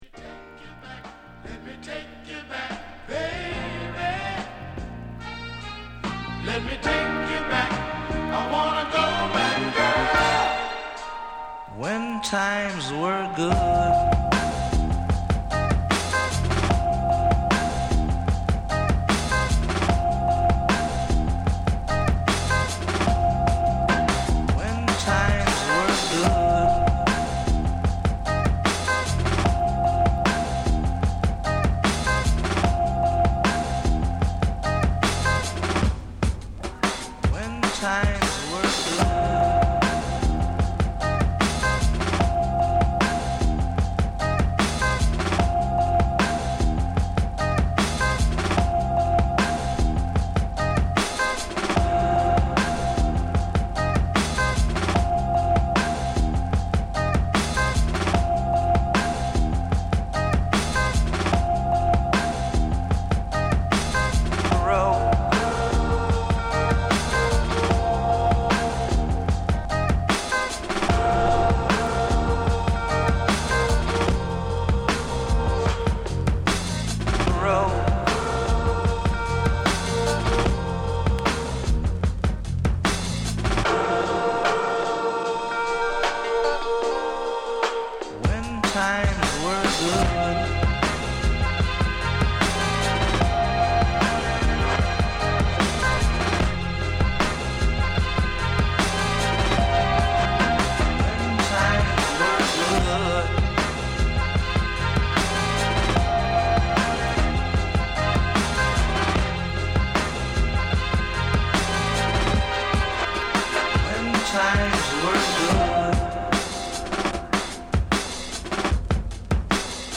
fine Latin beats in the molds of nu cumbia, hip-hop and dub
Electronic Funk/Soul International
vintage Latin psych rock and funk plus indie hip-hop